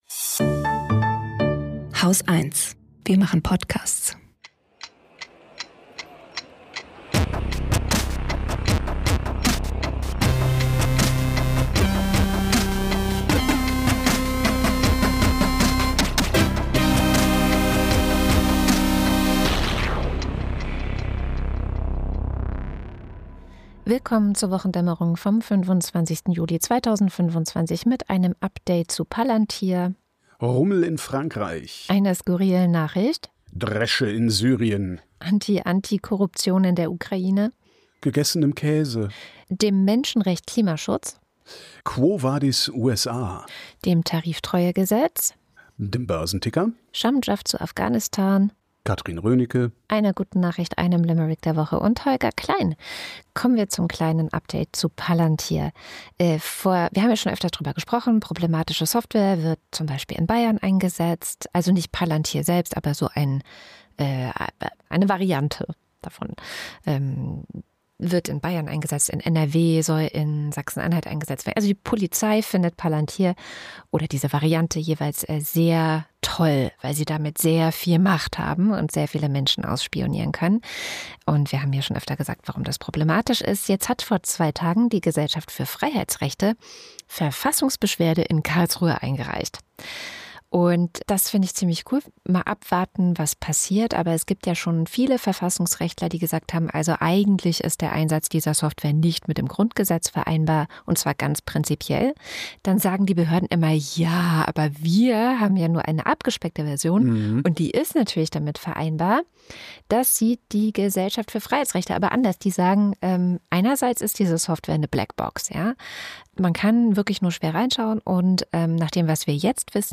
Download - Der Lockdown in Shanghai - Interview mit Anonyma | Podbean